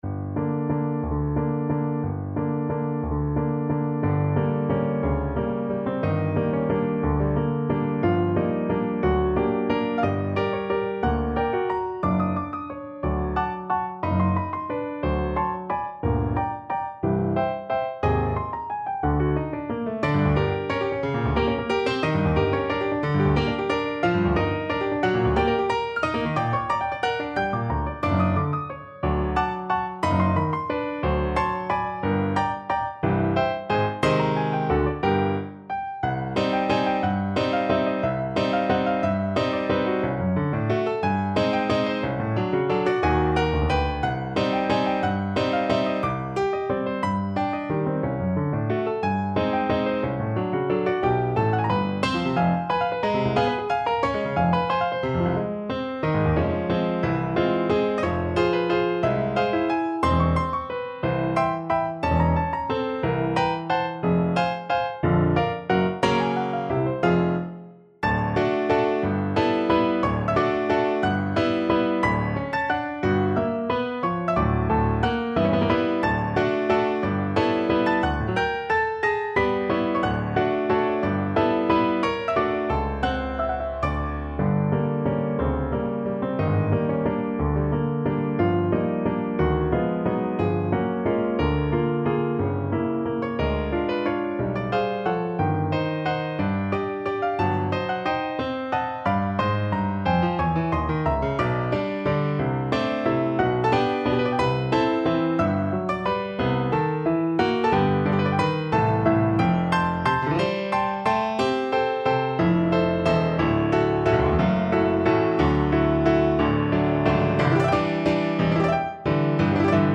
Piano version
No parts available for this pieces as it is for solo piano.
3/4 (View more 3/4 Music)
Allegro espressivo .=60 (View more music marked Allegro)
Piano  (View more Advanced Piano Music)
Classical (View more Classical Piano Music)